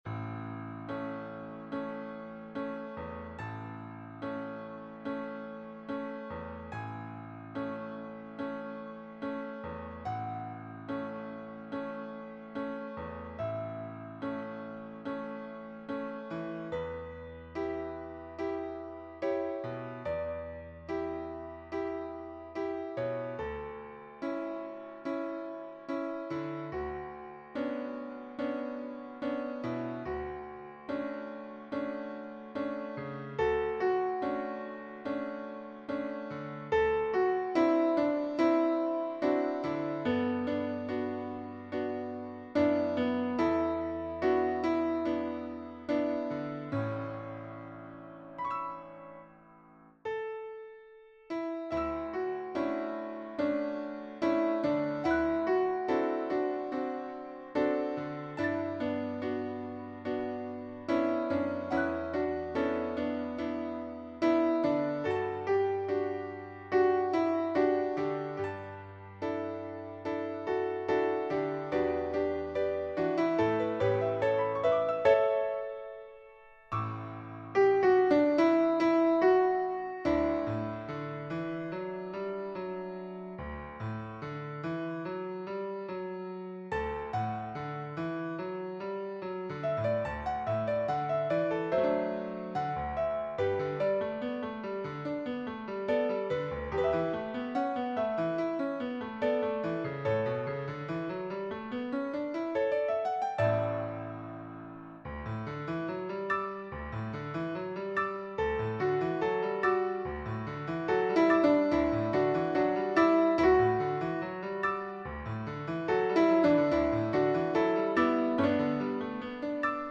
2-part SA female choir and piano
世俗音樂
C段為全曲高點，在最激昂處馬上用輕柔的聲音唱出對於這片滋養他夢想的土地無限感念，鋼琴使用前奏的動機將心境緩和下來。